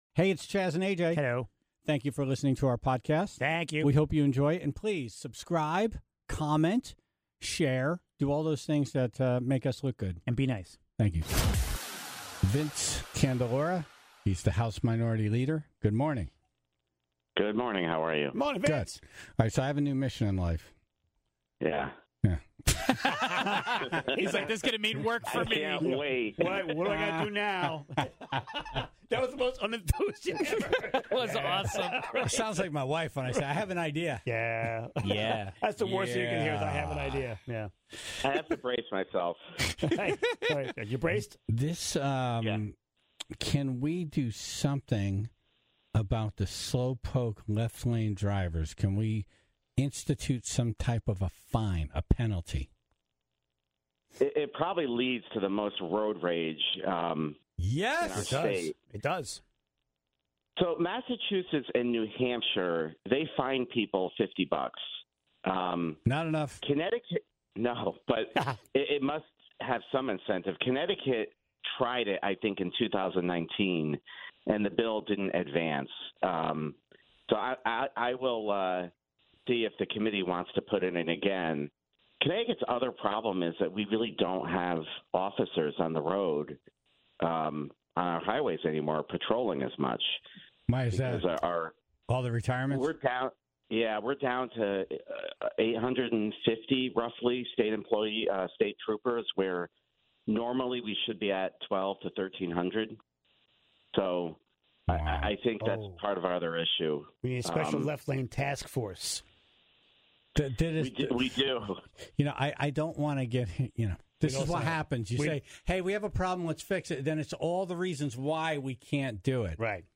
More calls from the Tribe with stories of scams, swindlers and cheats.